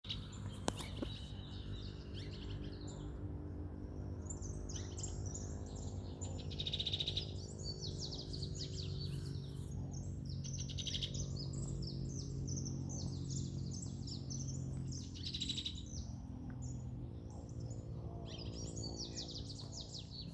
Crested Hornero (Furnarius cristatus)
Location or protected area: Nono
Condition: Wild
Certainty: Photographed, Recorded vocal
Hornerito-copeton_1_1_1.mp3